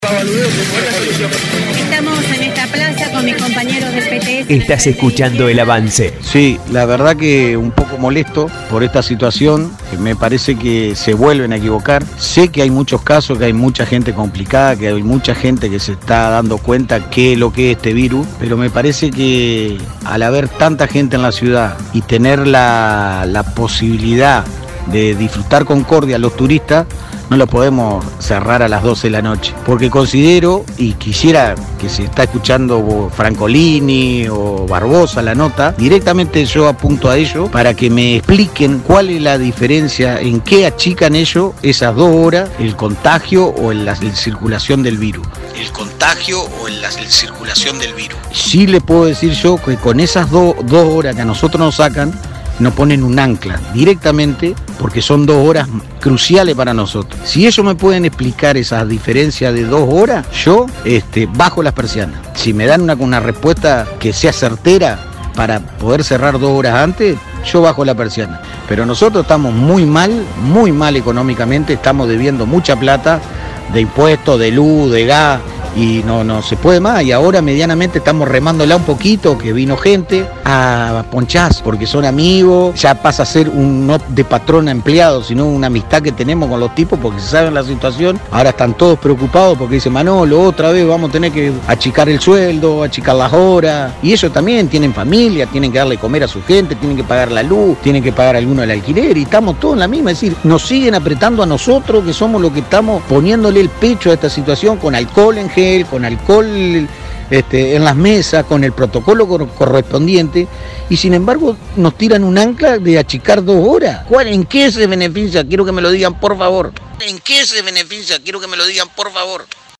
programa Radial 102.1 FM de Concordia.